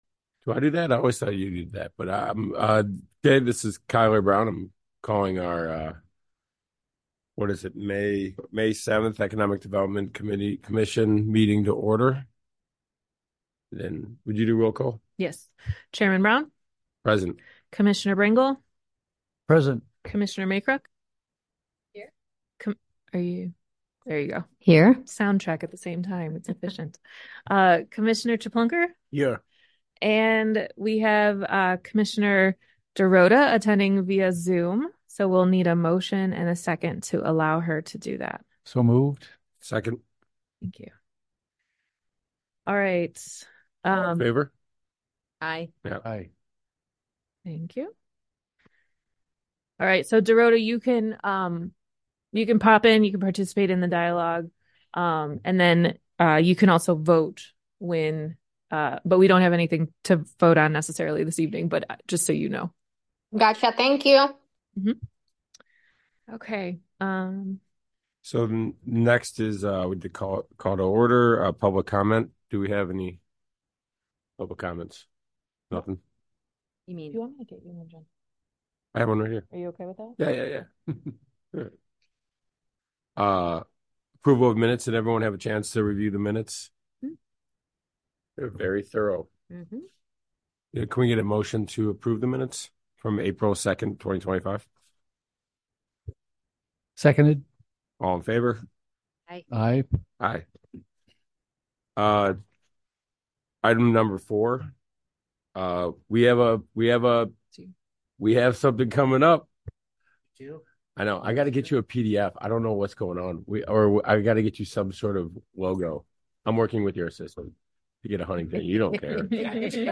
Economic Development Commission Meeting
Village Hall - 400 Park Avenue - River Forest - IL - 1st Floor - Community Room